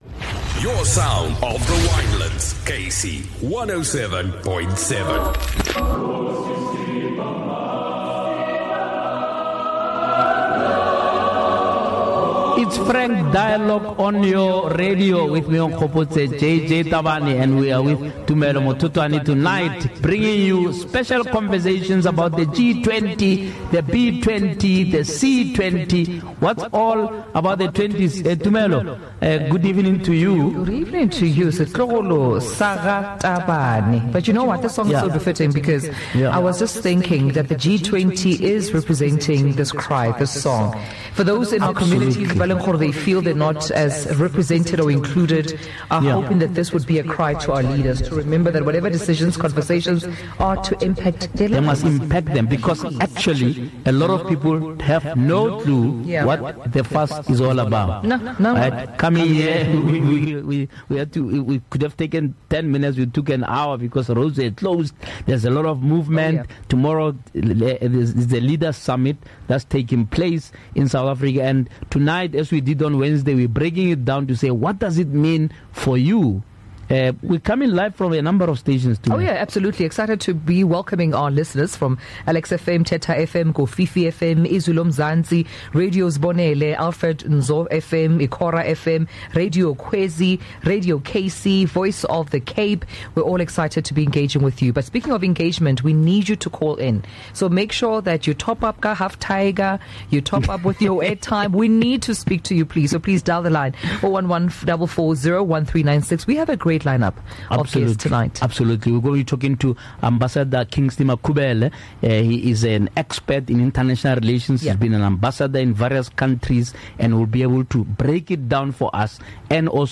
This powerful one-hour syndication programme will bring clarity, insight, and compelling discussion on global decisions shaping our local reality.